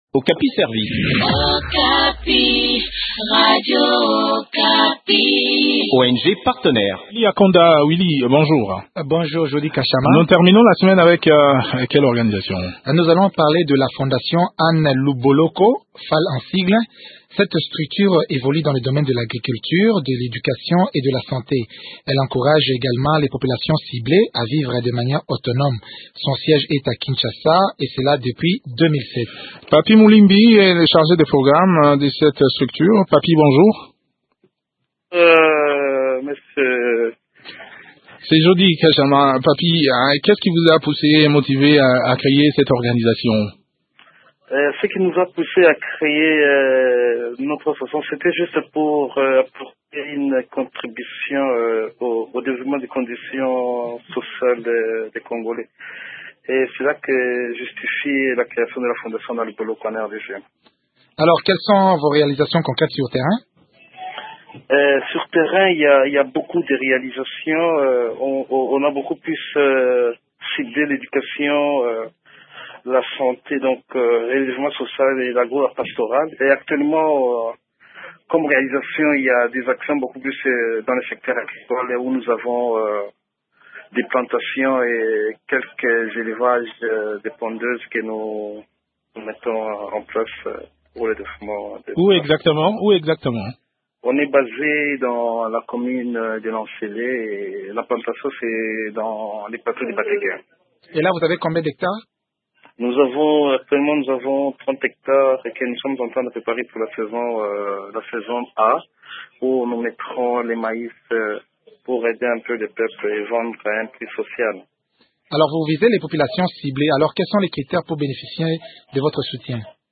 Le point sur les activités de cette structure dans cet entretien